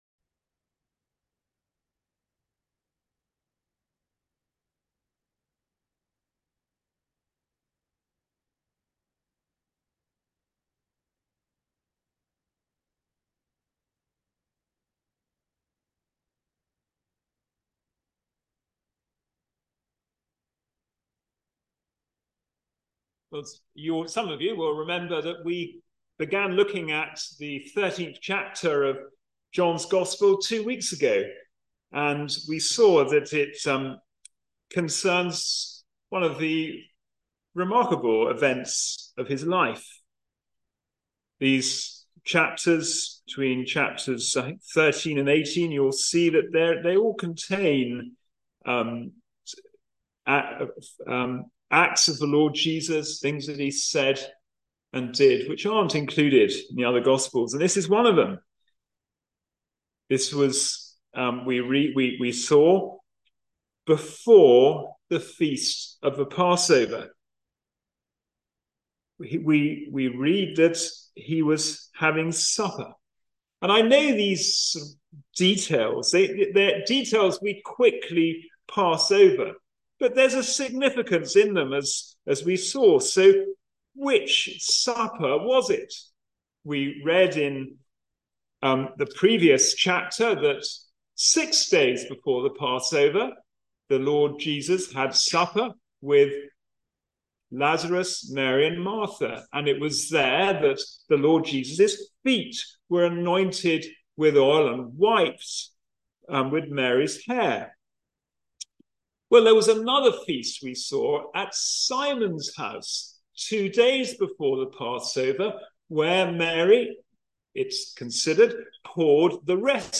Passage: John 13:1-15 Service Type: Sunday Morning Service Foot-Washing